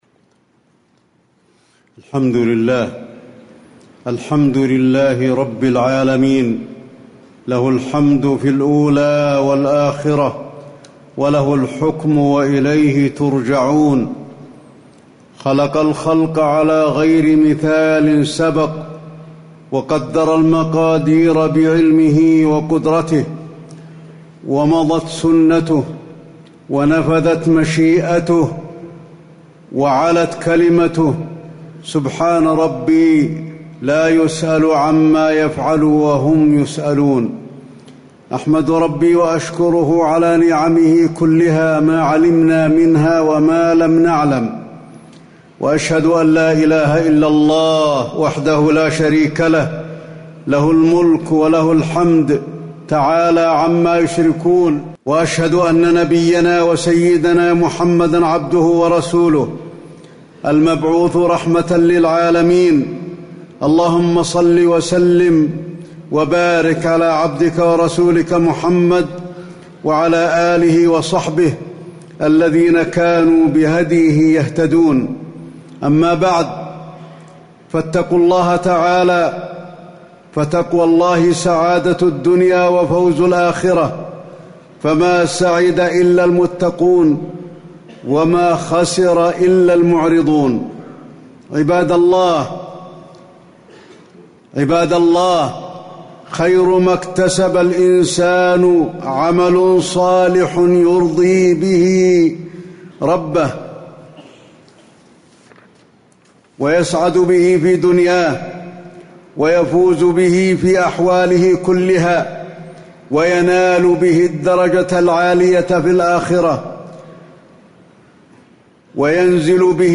تاريخ النشر ١٣ شوال ١٤٣٨ هـ المكان: المسجد النبوي الشيخ: فضيلة الشيخ د. علي بن عبدالرحمن الحذيفي فضيلة الشيخ د. علي بن عبدالرحمن الحذيفي الاجتهاد في الخيرات بعد رمضان